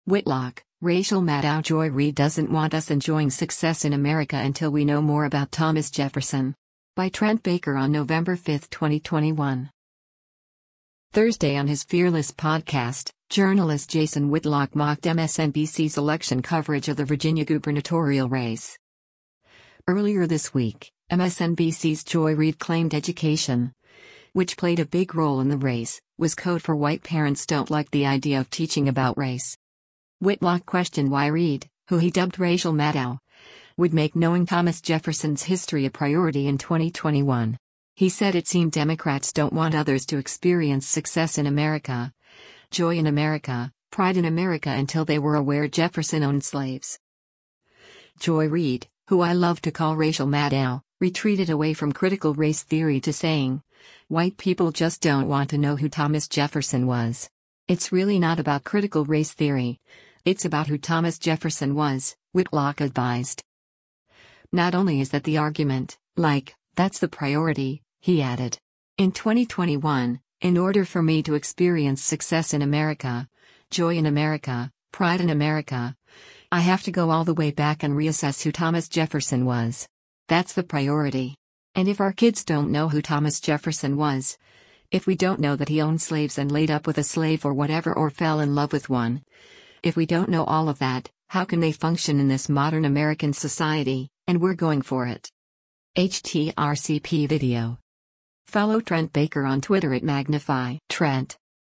Thursday on his “Fearless” podcast, journalist Jason Whitlock mocked MSNBC’s election coverage of the Virginia gubernatorial race.